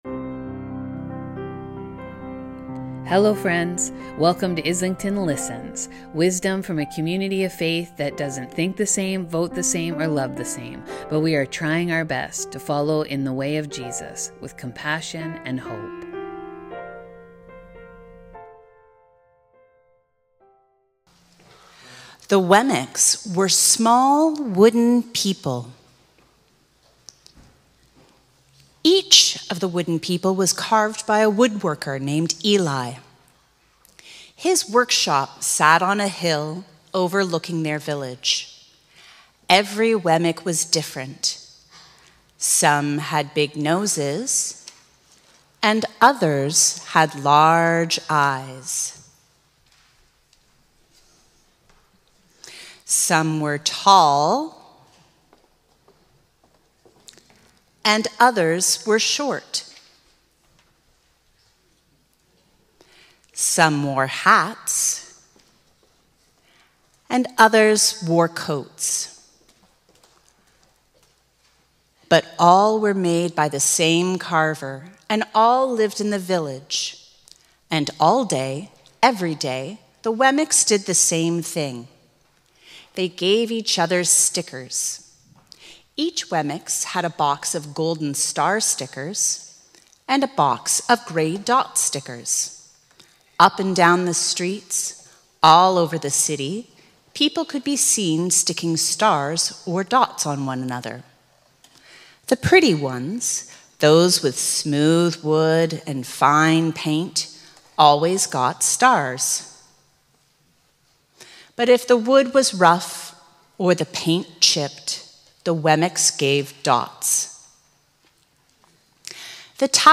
This Sunday, our children performed the book "You are Special" written by Max Lucado, illustrated by Sergio Martinez, and published by Crossway Books.